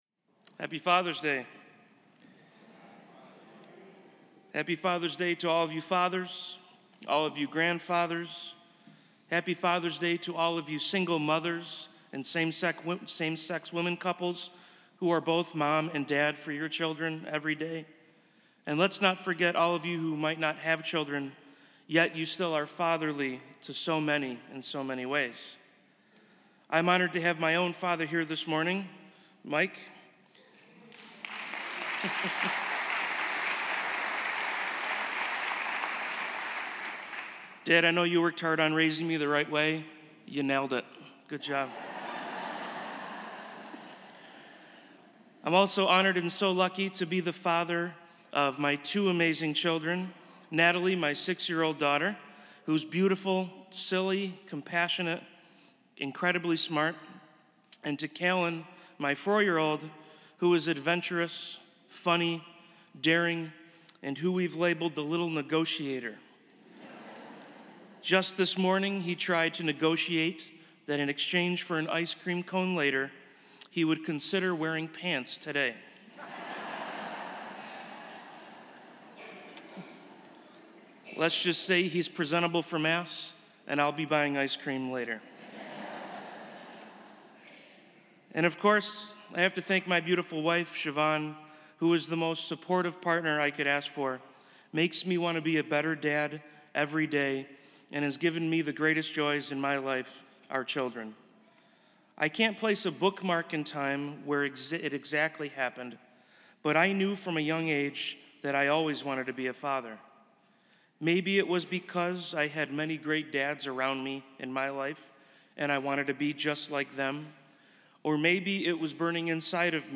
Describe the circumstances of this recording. This is a Sunday morning mass at Spiritus Christi Church in Rochester, NY.